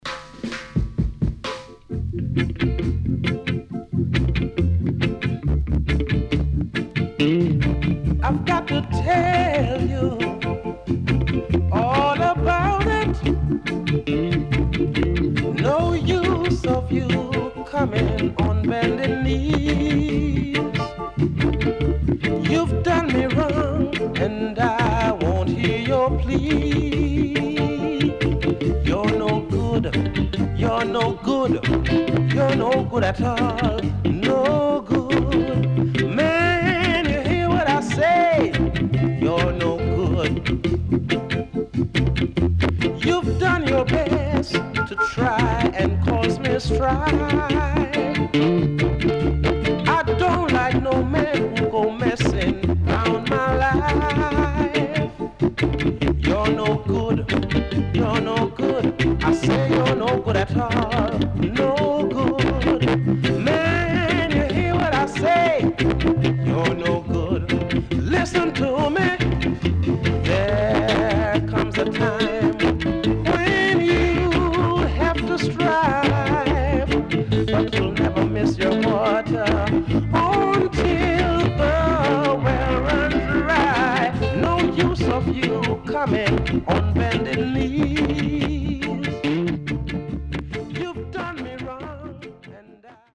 HOME > Back Order [VINTAGE 7inch]  >  EARLY REGGAE
SIDE A:少しチリノイズ入りますが良好です。